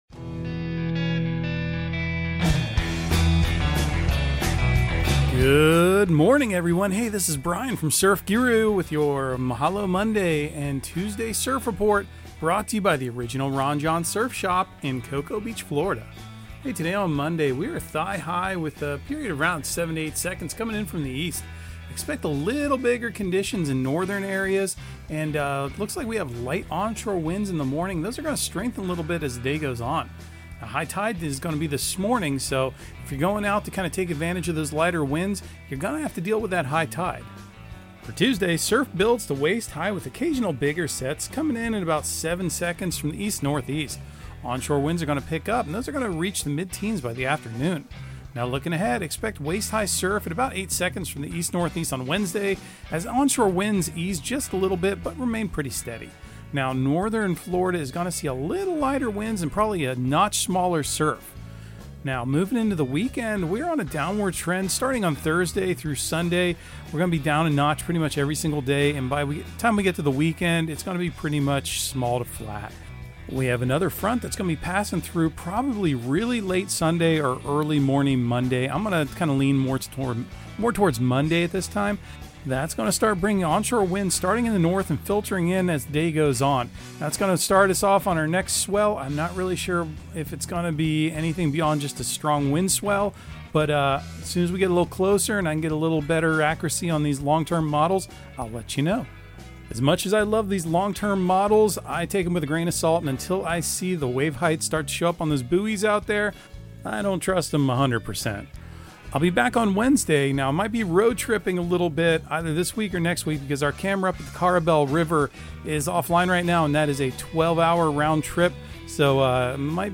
Audio surf report and surf forecast on April 28 for Central Florida and the Southeast. Your host will also enlighten you on current events in the surfing industry and talk about events and entertainment happenings in the local and regional area.